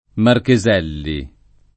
[ marke @$ lli ]